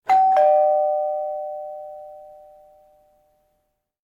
doorbell2.wav